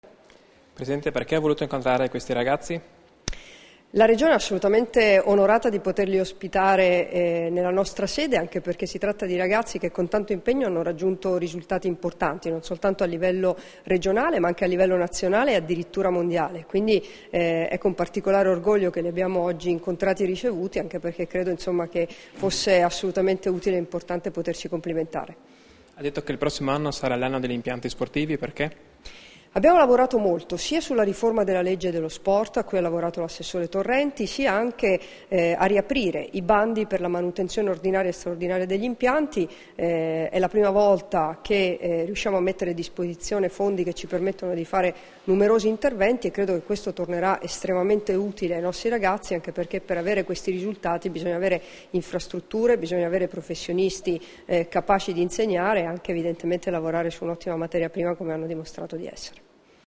Dichiarazioni di Debora Serracchiani (Formato MP3) [958KB]
alla cerimonia di premiazione delle quattro scuole che hanno vinto il titolo nazionale ai Campionati studenteschi di Atletica leggera nel 2014 e nel 2015, rilasciate a Trieste il 14 dicembre 2015